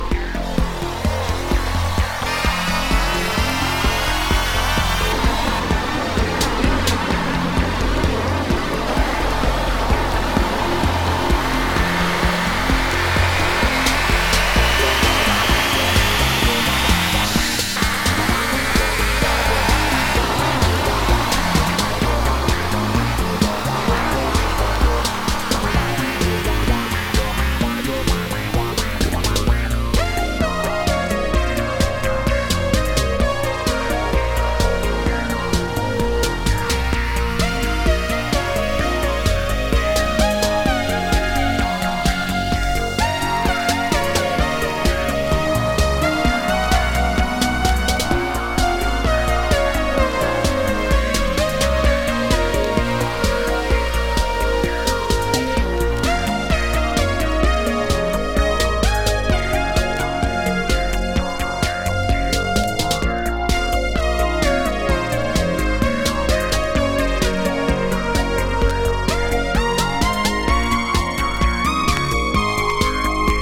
A面はUSシンガーが日本語交じりに歌うポップス。強力なのはB面のコズミックインスト